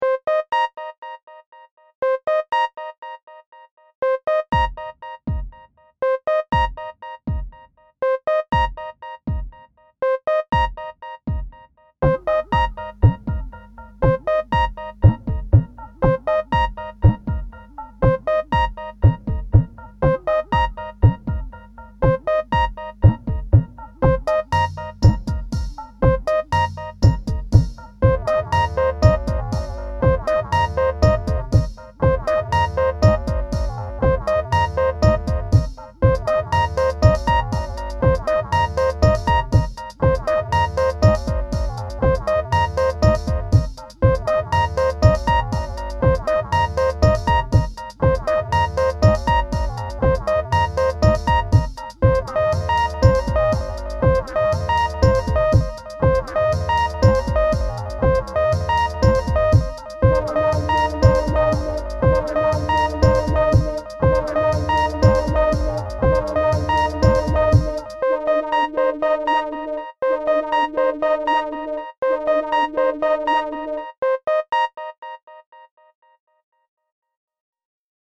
Pieza de electrónica minimalista
Música electrónica
sintetizador